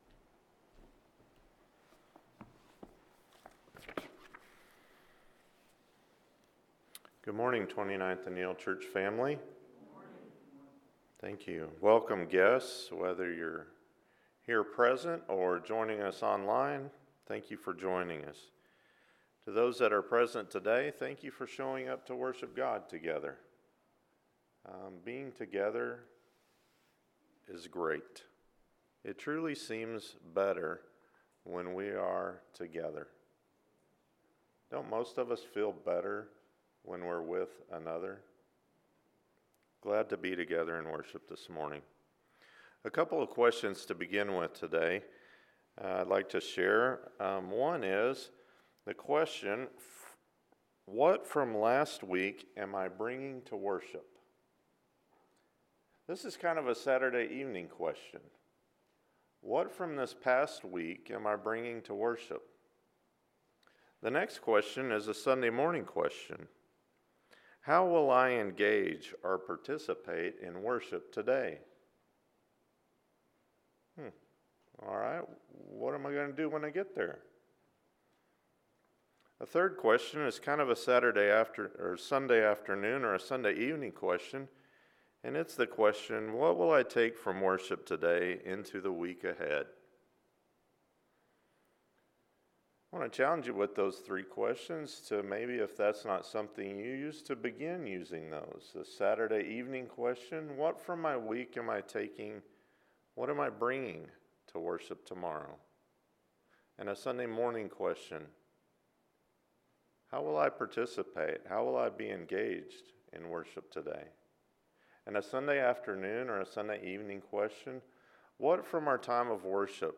When you pray… – Matthew 6:5-13 – Sermon